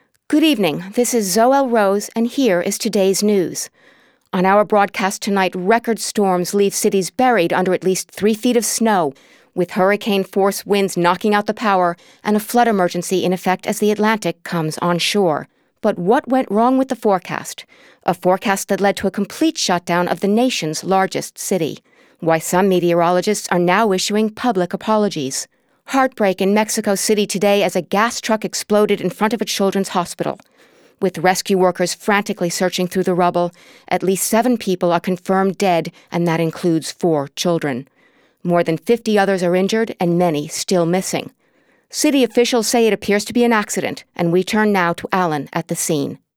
Soft-spoken, genuine, animated, intelligent, confident, soothing, caring, conversational.
Sprechprobe: Sonstiges (Muttersprache):